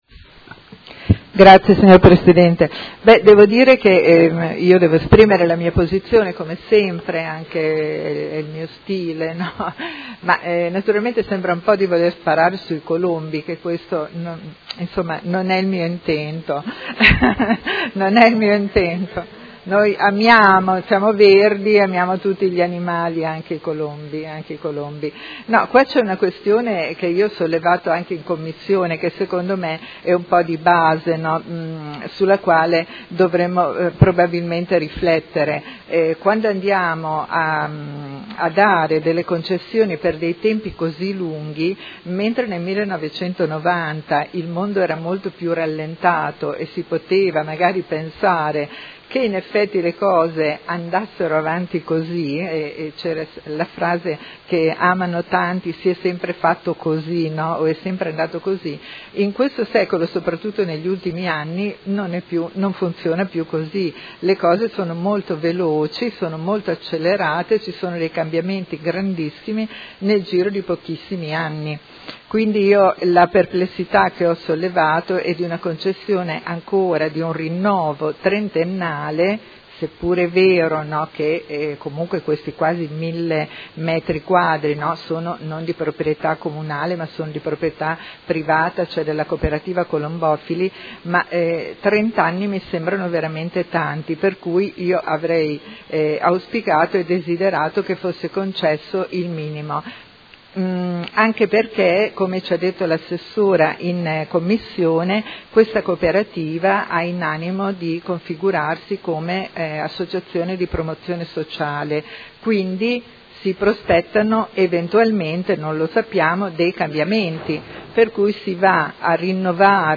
Paola Aime — Sito Audio Consiglio Comunale